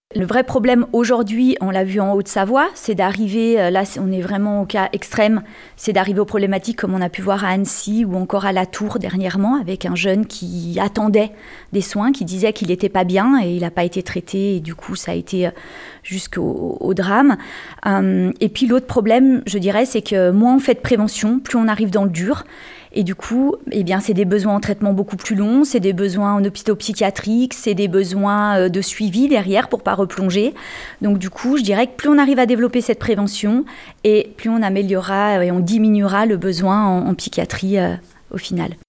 Ce qui a des conséquences parfois dramatiques, comme le rappelle Christelle Petex, la députée LR de la troisième circonscription de Haute-Savoie.